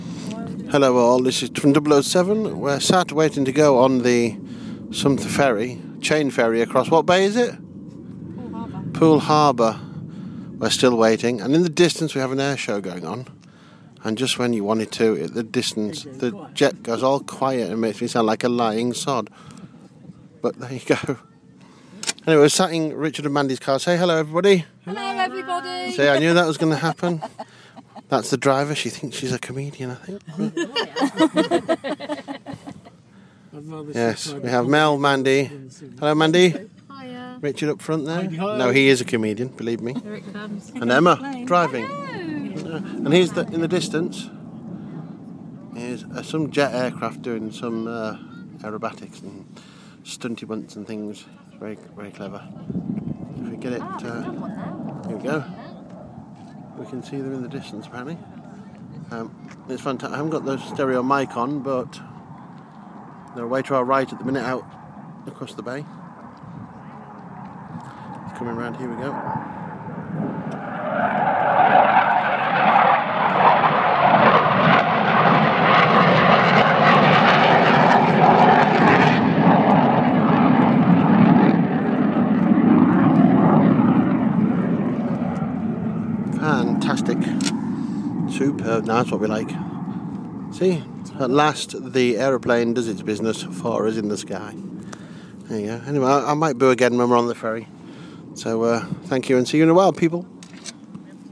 Chain ferry and jet display